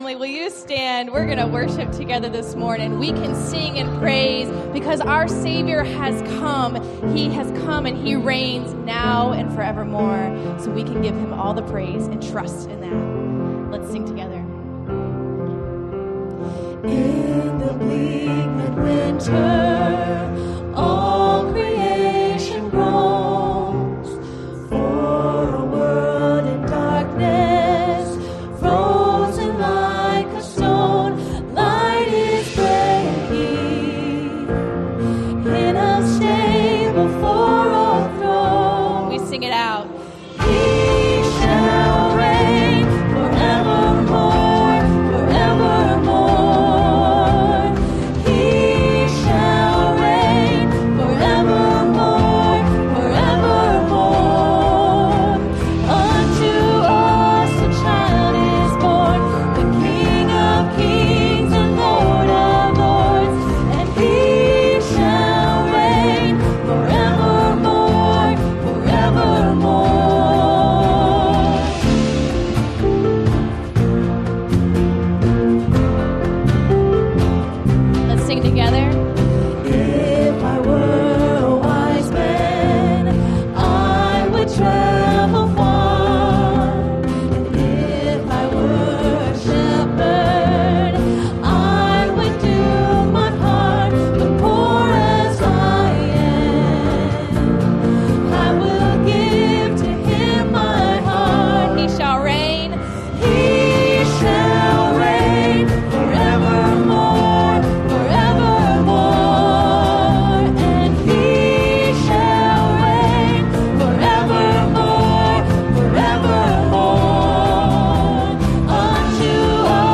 Installation Service | September 15, 2024
Sep 15, 2024 | by Various Speakers